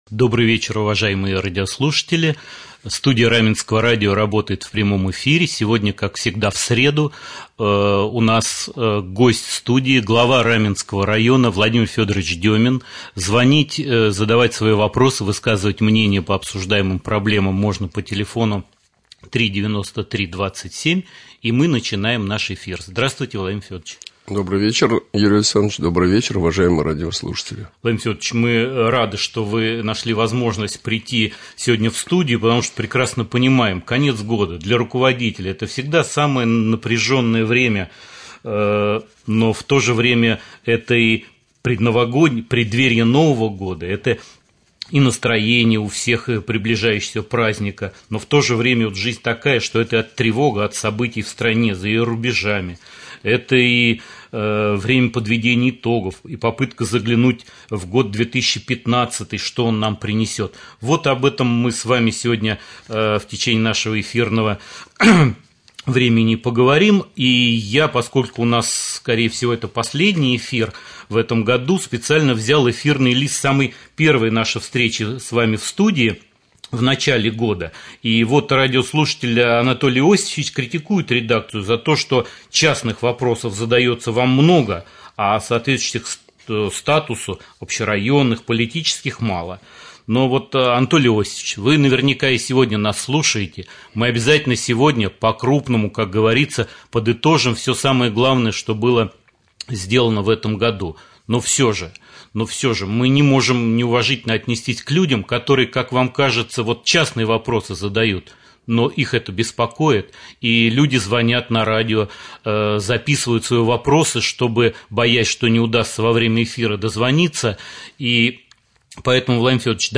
Прямой эфир. Гость студии Глава Раменского муниципального района Владимир Федорович Демин.